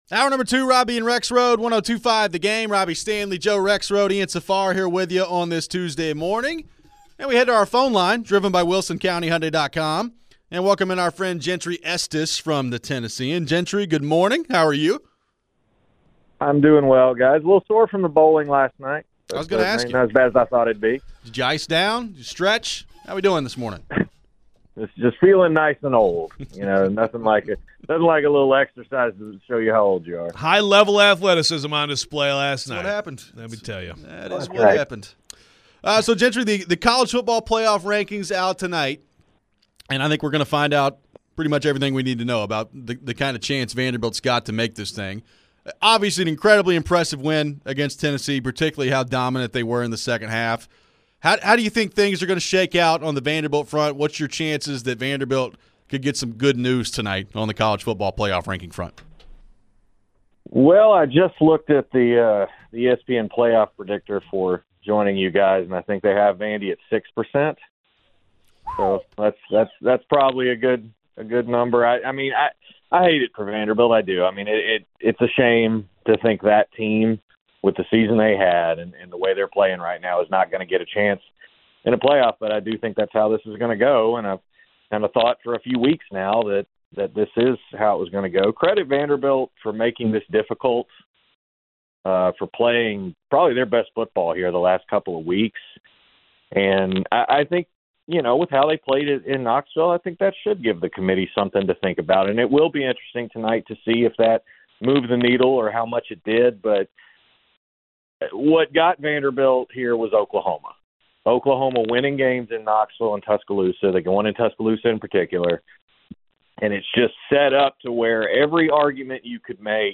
Interview; CFB News and Notes